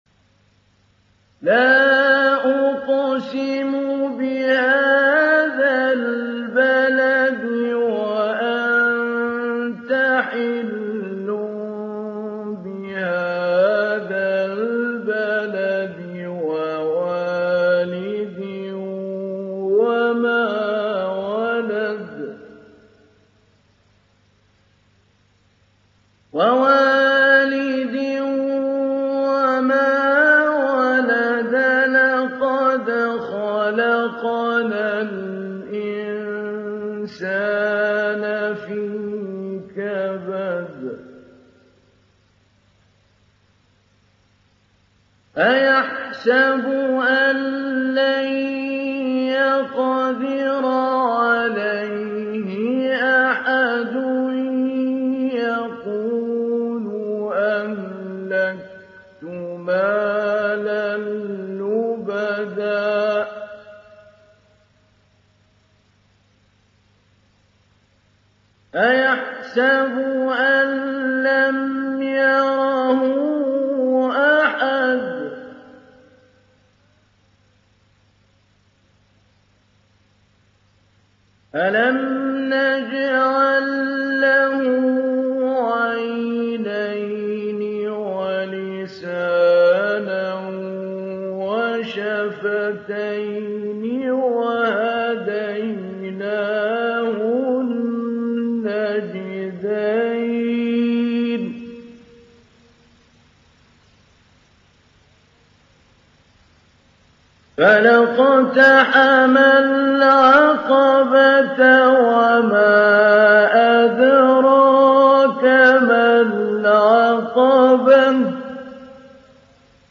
Télécharger Sourate Al Balad Mahmoud Ali Albanna Mujawwad